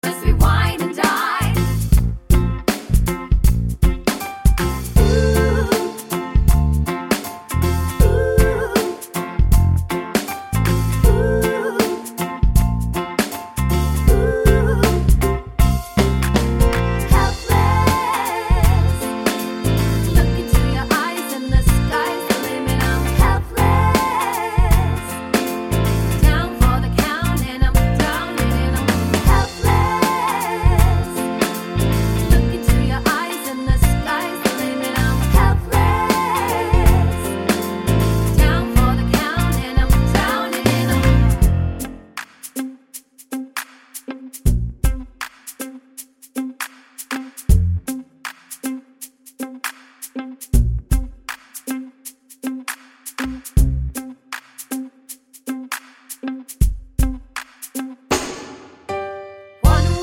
no Backing Vocals Musicals 4:18 Buy £1.50